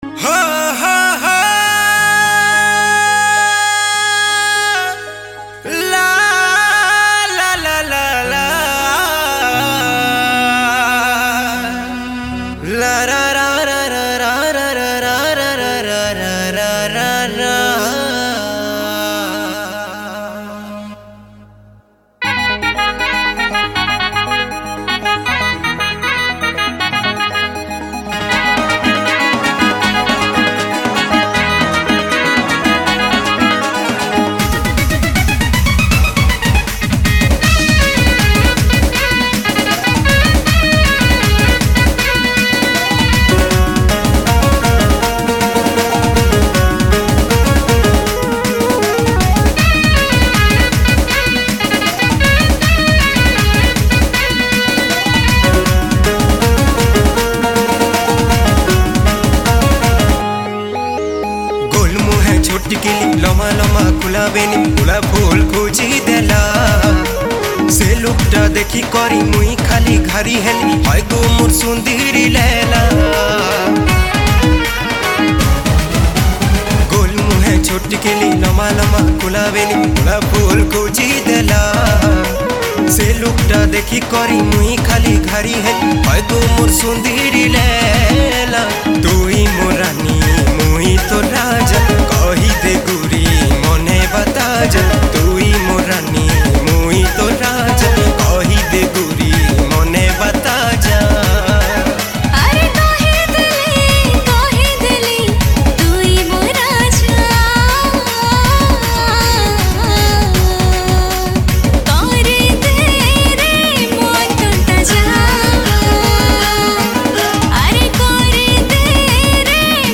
New Sambalpuri Song 2026